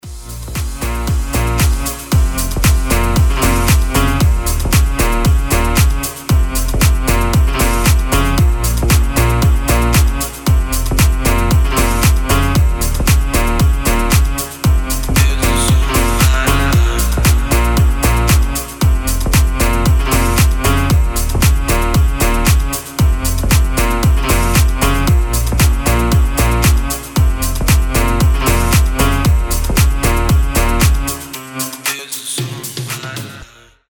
Рингтоны DubStep
Громкие рингтоны / Клубные рингтоны
эпичные
Дабстеп